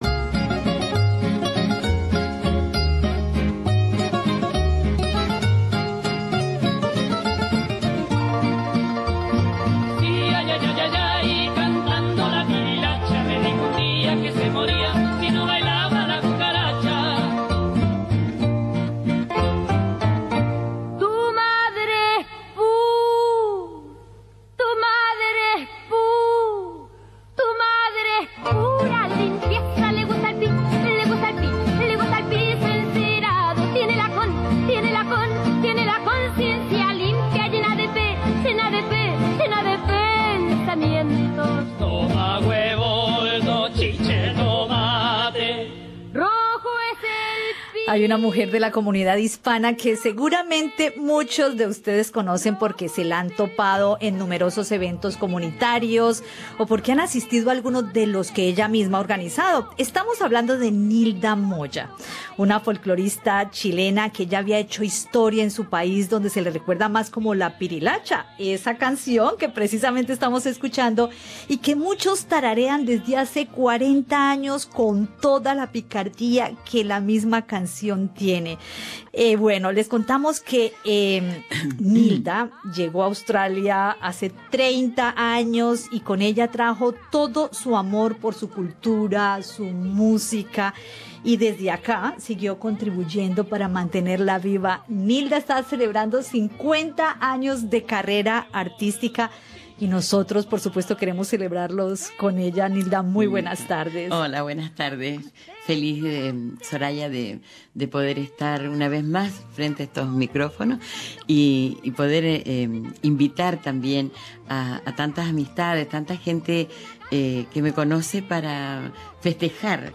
SBS en español